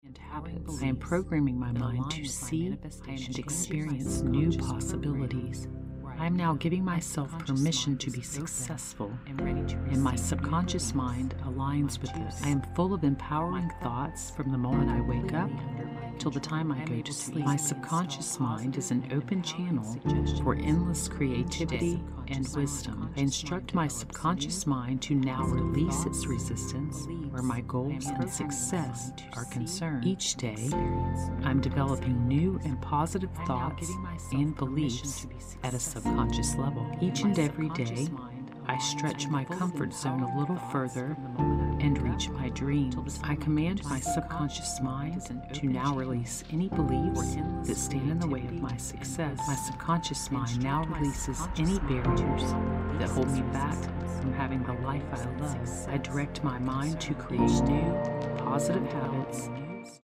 This full 8-hour MP3 has over 17,000 subliminal and non-subliminal affirmation impressions that will permeate your subconscious mind with powerful I AM affirmations that connect you with your conscious power to be in charge of your life and create and attract your desires. It is combined with a 528 Hz binaural tone, known as the “Miracle Tone” to assist you in manifesting miracles and promoting a calm and confident state.
The affirmations move from ear to ear and back to center to strongly infuse the messages.